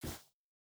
Shoe Step Snow Medium D.wav